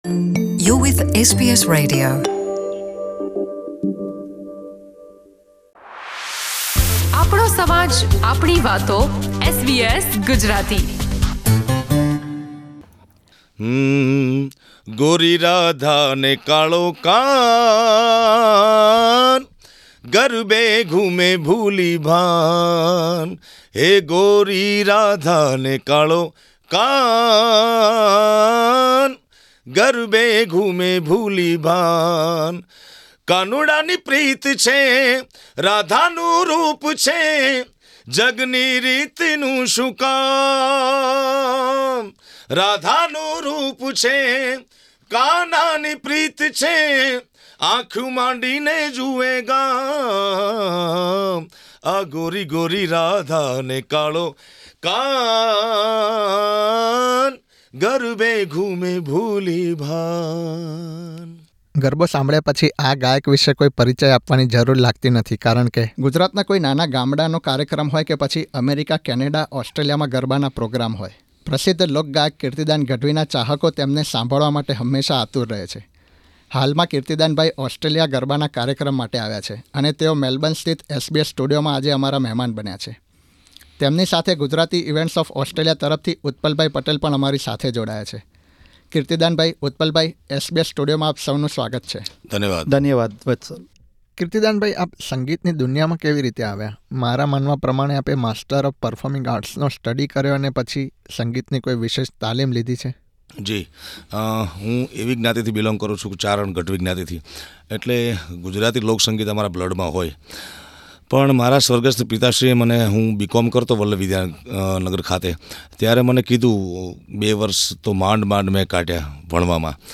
ઓડિયો ઇન્ટરવ્યુંમાં પૂછવામાં આવેલા પ્રશ્નનો સાચો જવાબ આપનારા પ્રથમ 10 વિજેતાઓને ગરબાનો એક પાસ આપવામાં આવશે.